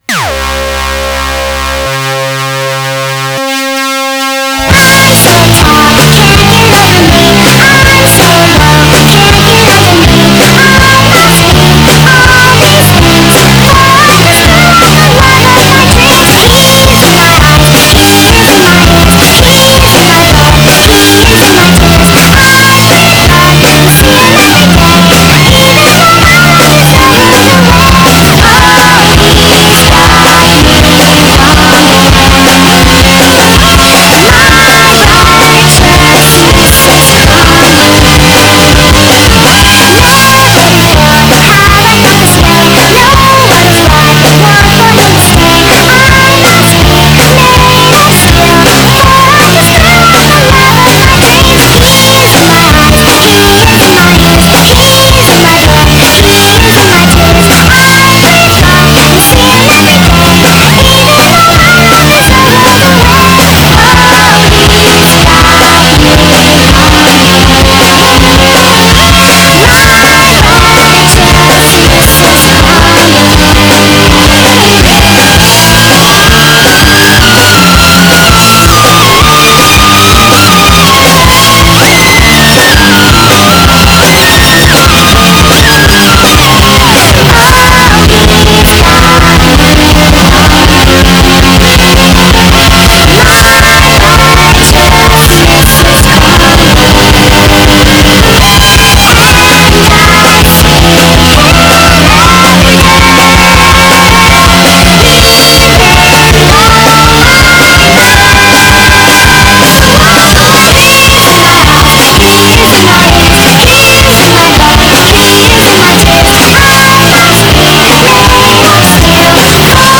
Nightcore